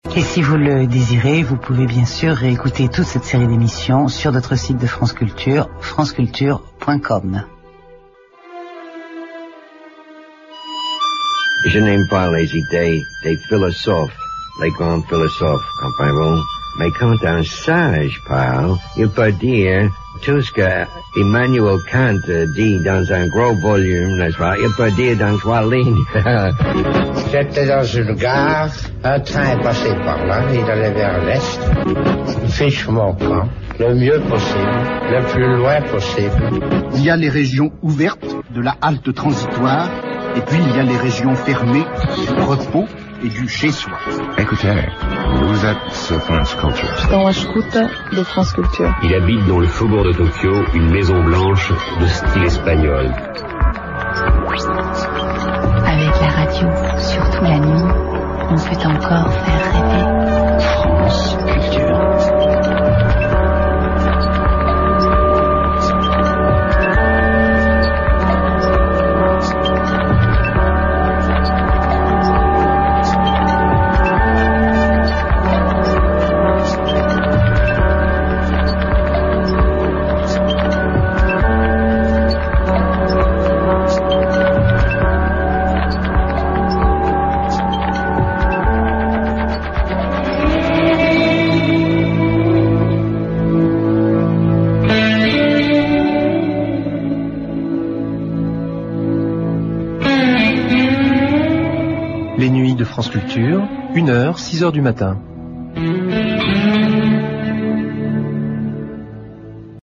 Mais elle est désormais précédée par 1 ou 2 minutes d'auto-promo.
Oui, c'est celui d'Aoûtqu'on a entendu toute l'année, j'ai aussi reconnu la voix de Chancel: "Il habite dans les faubourgs de Tokyo une maison blanche de style espagnol."
Dans ce bobino qui marque l'entrée des nuits depuis presque une année, on peut reconnaitre la voix de Chancel, et peut-être celle de Man Ray, et aussi celle de Fernandel apparemment.